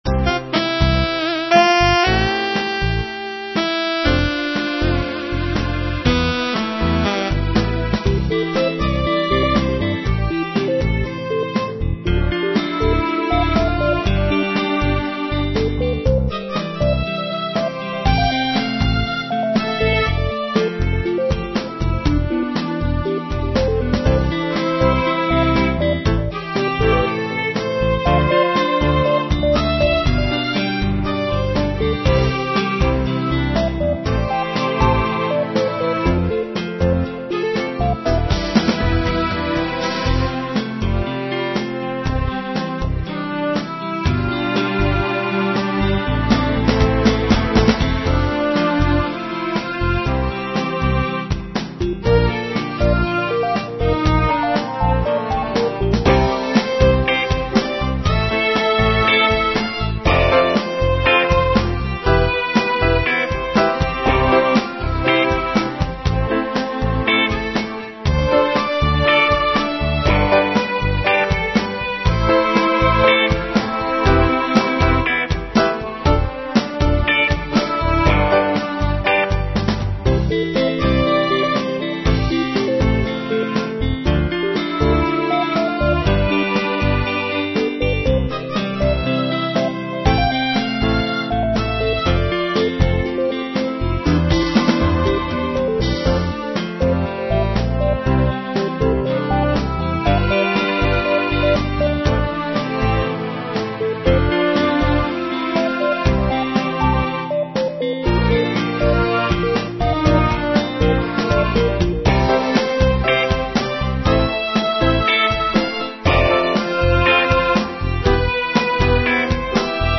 Joyful Country-Rock composition